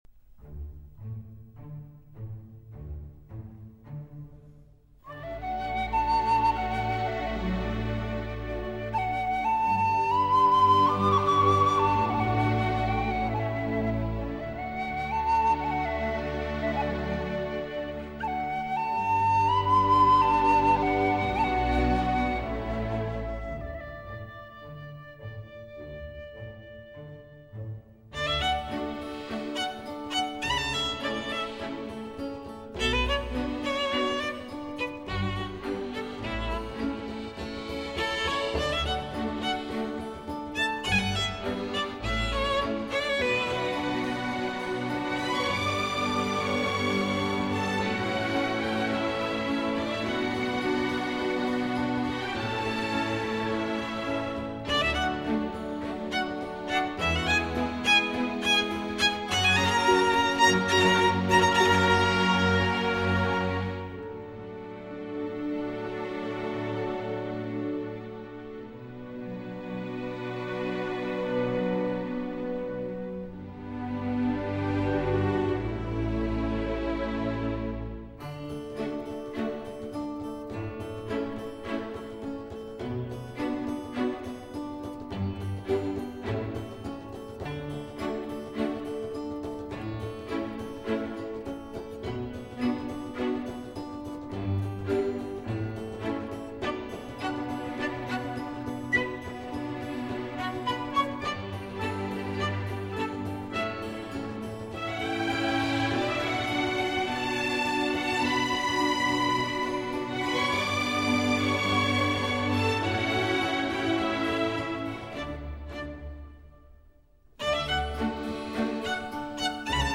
موزیک بی کلام